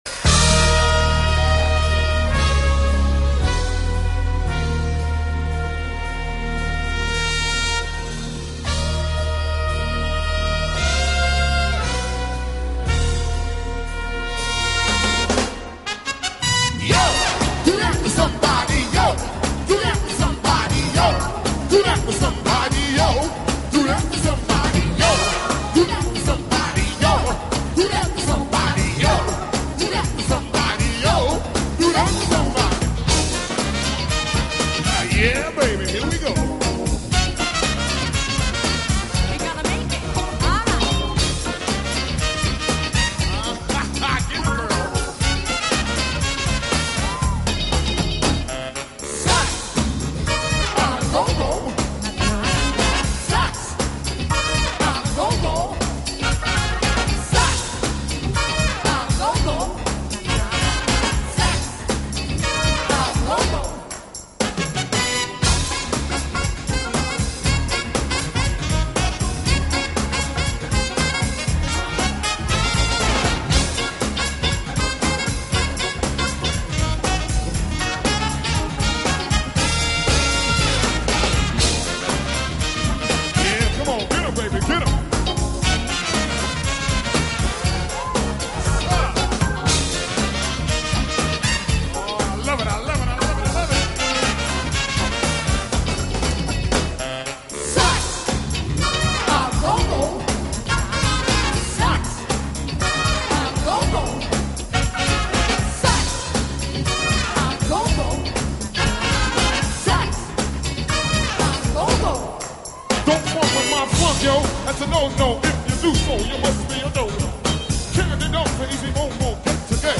亮的金发和俏丽的面庞，她吹奏中音萨克斯的技巧也堪称一流。